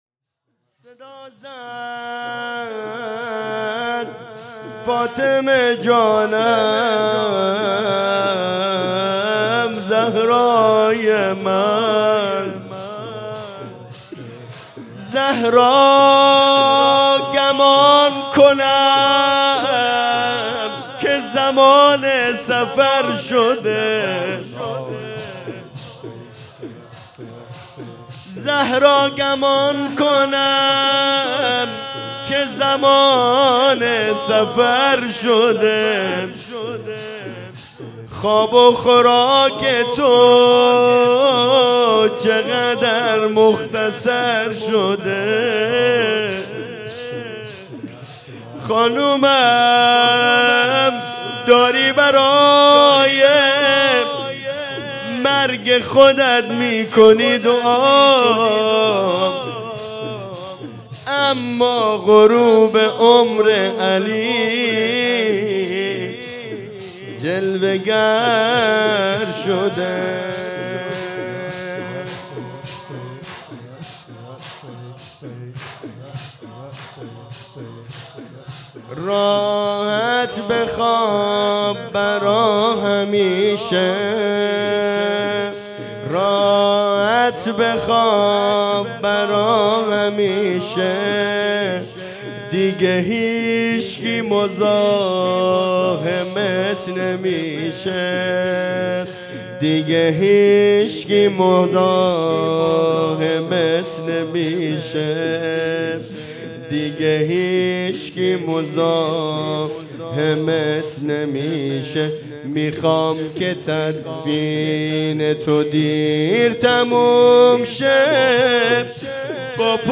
زمینه فاطمیه راحت بخواب برا همیشه هیئت رایة الزهرا سلام الله علیها یزد